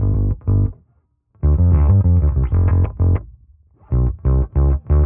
Index of /musicradar/dusty-funk-samples/Bass/95bpm
DF_PegBass_95-G.wav